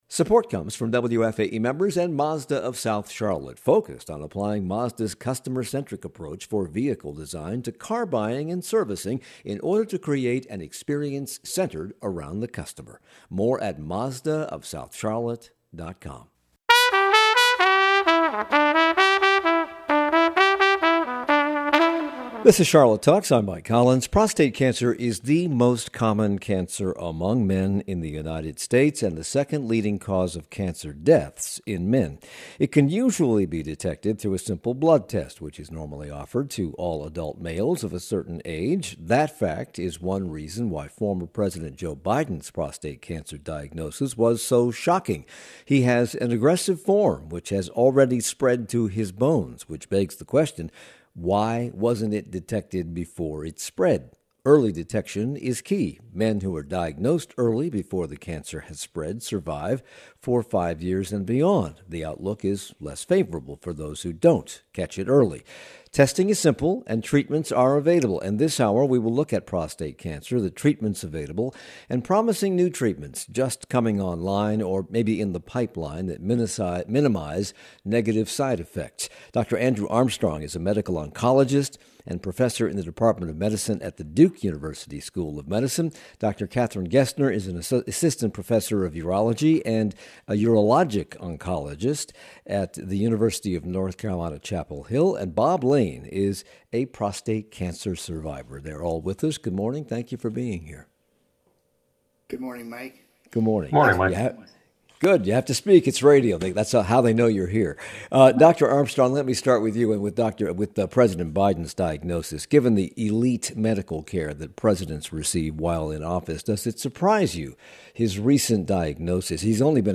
On the next Charlotte Talks, we look at the treatment, screening and testing options for prostate cancer patients. We also look at promising new treatment research recently released by Duke University and hear from a prostate cancer survivor.